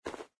在薄薄的雪地上脚步声－左声道－YS070525.mp3
通用动作/01人物/01移动状态/02雪地/在薄薄的雪地上脚步声－左声道－YS070525.mp3